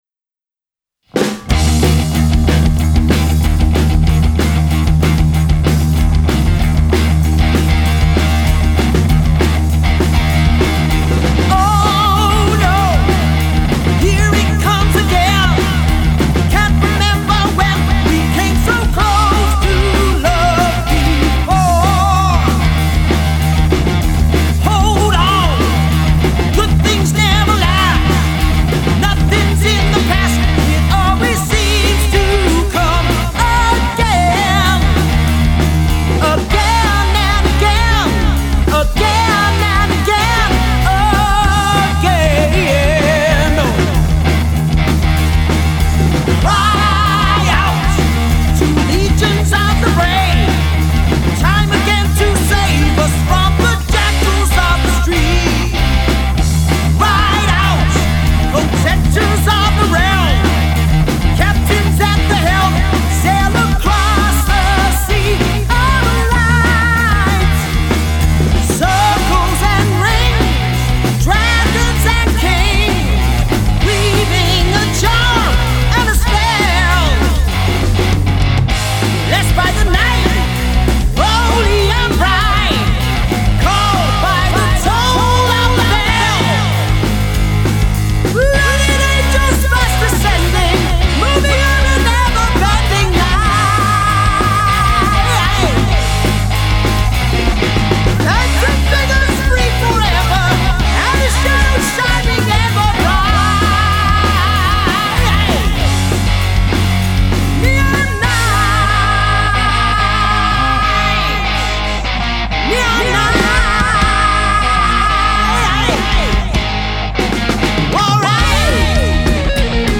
are a classic rock/metal covers band based in Bristol.